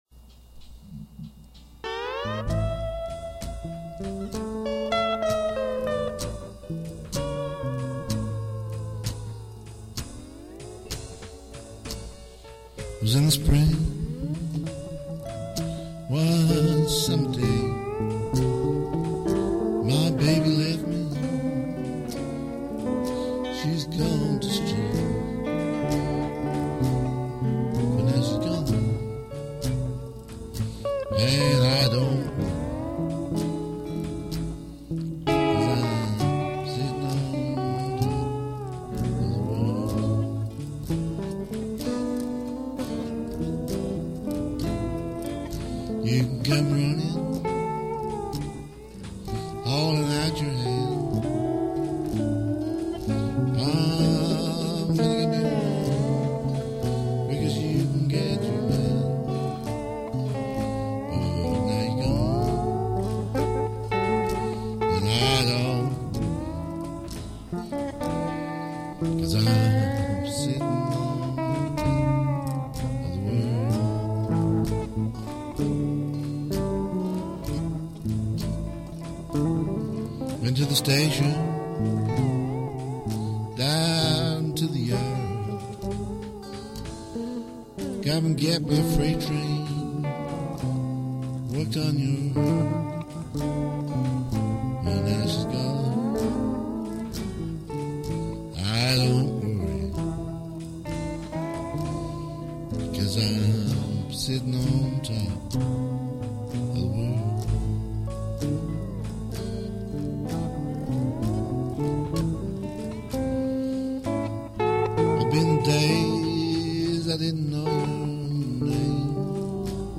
Multiple-tracked, one instrument at a time.
Country